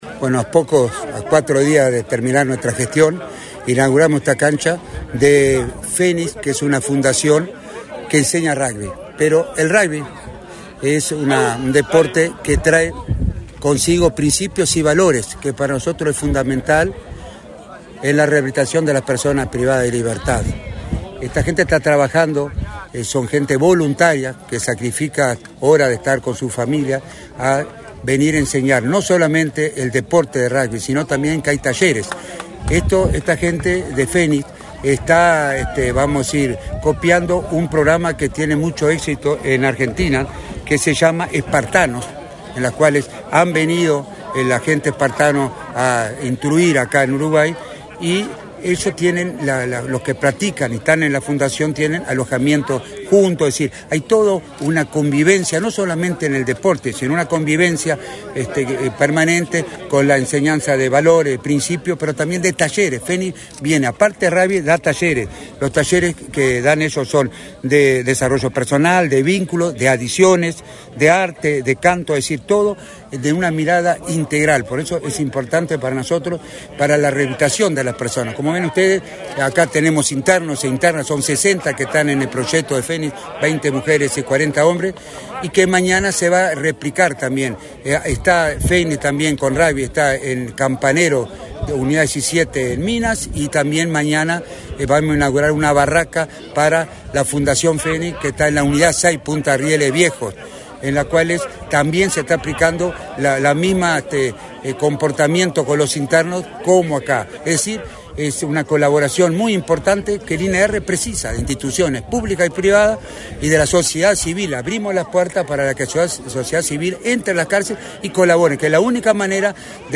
Declaraciones a la prensa del director del INR, Luis Mendoza
Tras participar en la inauguración de una cancha de rugby en el centro carcelario Las Rosas, en Maldonado, este 25 de febrero, el director del